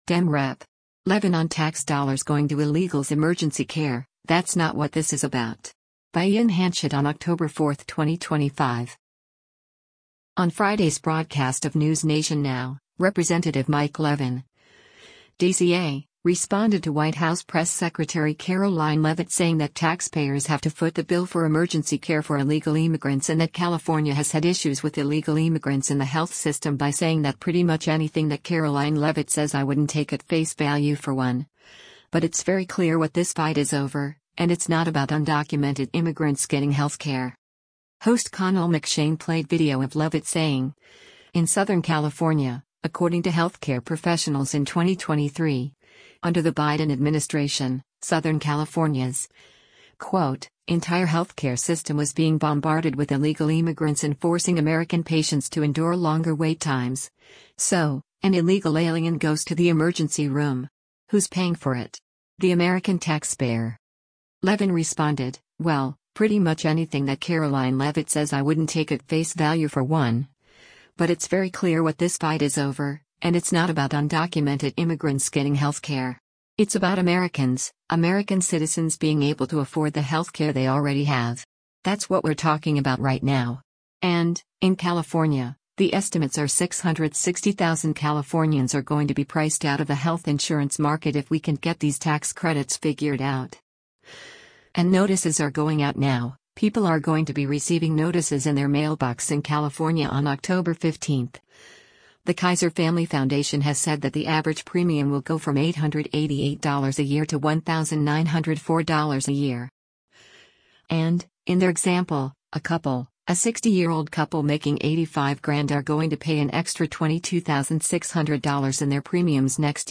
On Friday’s broadcast of “NewsNation Now,” Rep. Mike Levin (D-CA) responded to White House Press Secretary Karoline Leavitt saying that taxpayers have to foot the bill for emergency care for illegal immigrants and that California has had issues with illegal immigrants in the health system by saying that “pretty much anything that Karoline Leavitt says I wouldn’t take at face value for one, but it’s very clear what this fight is over, and it’s not about undocumented immigrants getting health care.”
Host Connell McShane played video of Leavitt saying, “In Southern California, according to healthcare professionals in 2023, under the Biden administration, Southern California’s, quote, entire healthcare system was being bombarded with illegal immigrants and forcing American patients to endure longer wait times. … So, an illegal alien goes to the emergency room. Who’s paying for it? The American taxpayer.”